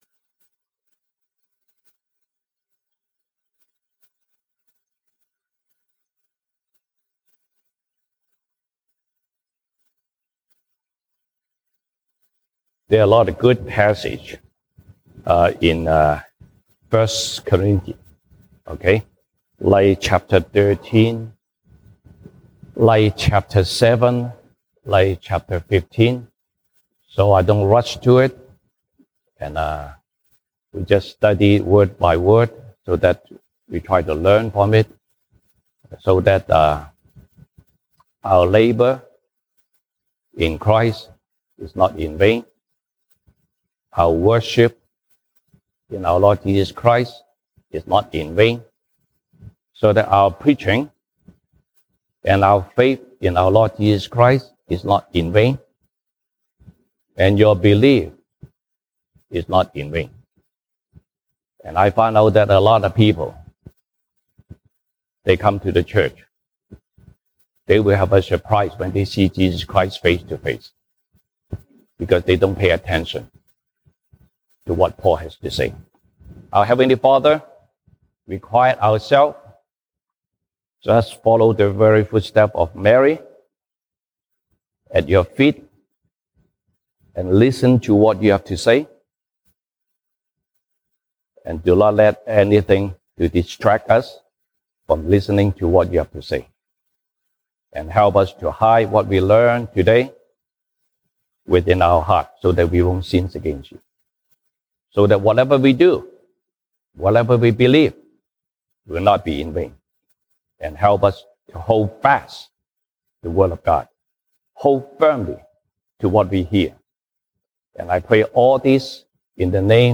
西堂證道 (英語) Sunday Service English: Test Everything